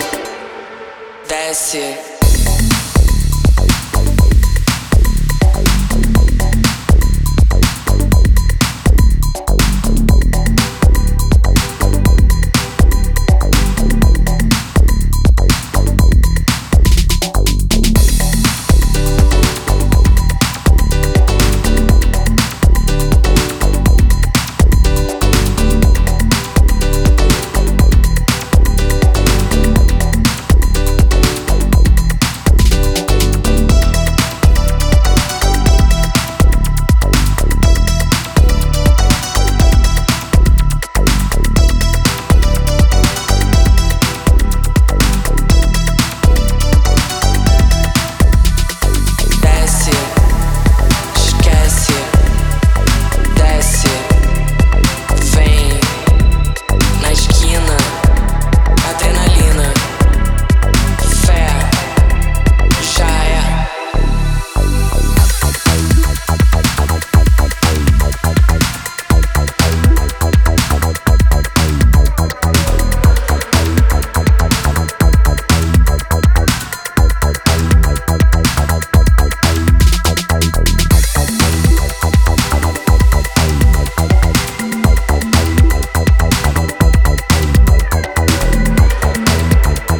80sタッチのリフとレイヴピアノから享楽的なムードが極まるエレクトロ・ファンク